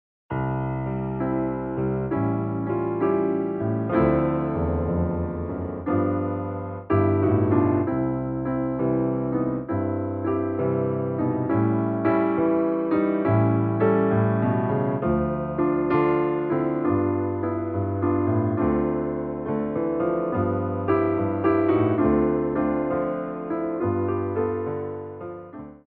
Piano Arrangements of Pop & Rock for Tap Class
SLOW TEMPO